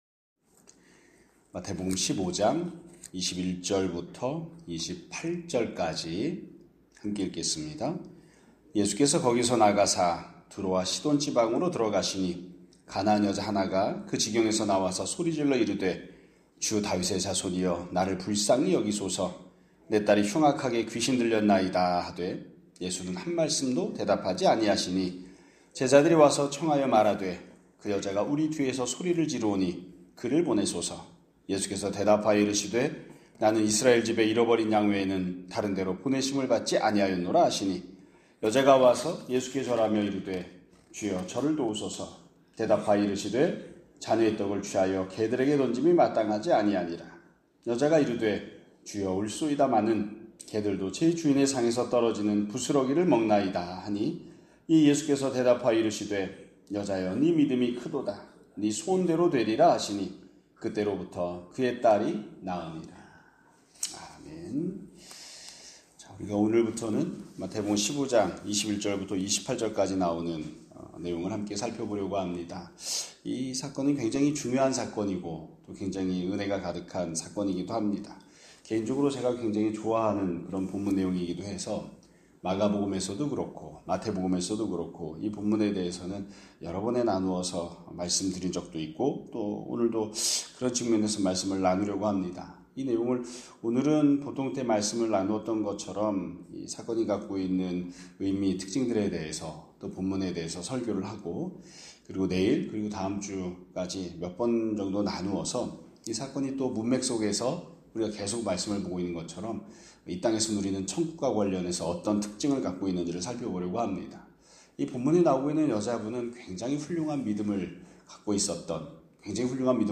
2025년 11월 6일 (목요일) <아침예배> 설교입니다.